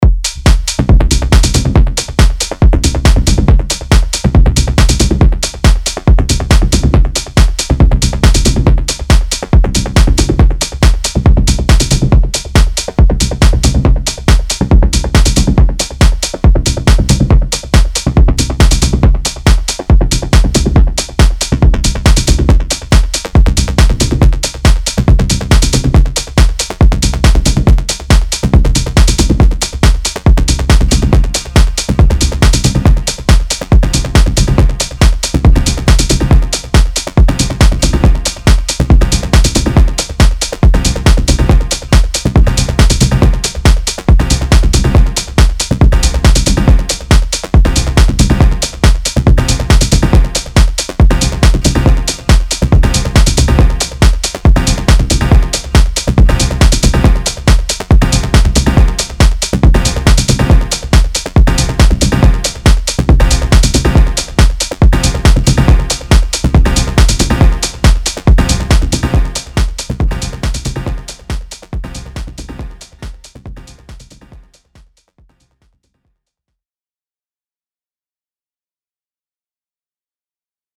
* Detroit Based Deep House Techno Producer